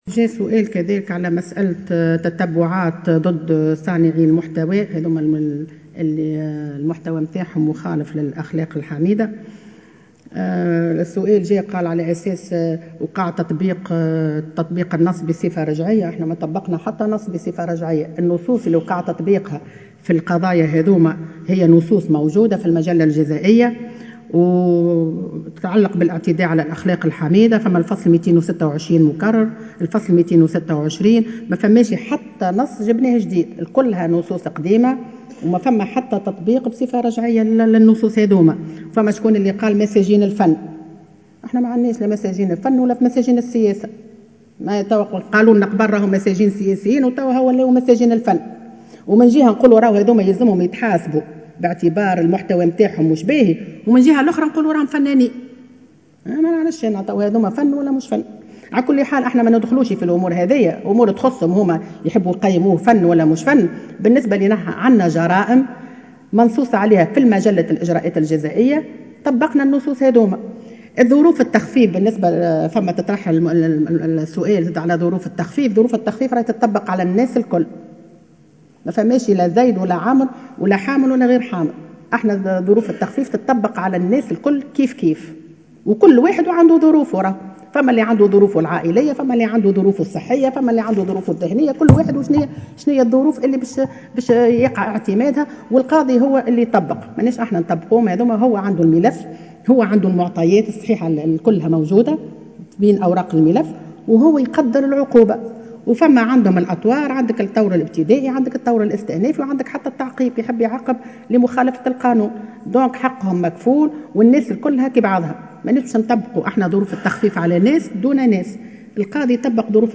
علّقت وزيرة العدل ليلى جفّال خلال أشغال الجلسة العامة في البرلمان على الأحكام الصادرة ضدّ عدد من صناّع المحتوى.